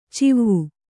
♪ civvu